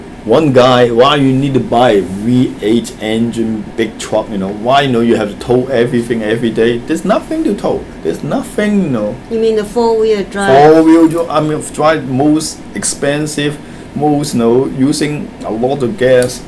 S1 = Taiwanese female S2 = Hong Kong male Context: S2 is talking about wastage in the United States.
The pronunciation is completely standard.
In fact, S2 soes not pronounce voiceless TH like this.